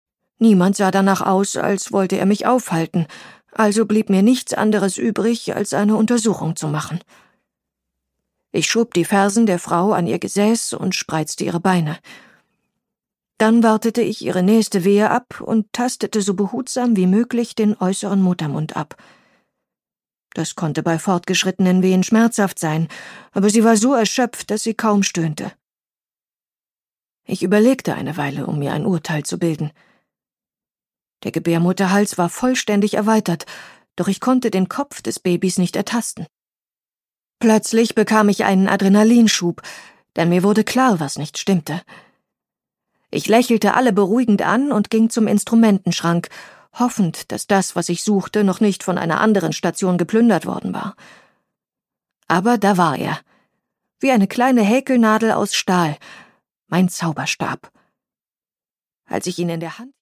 Produkttyp: Hörbuch-Download
Gelesen von: Luise Helm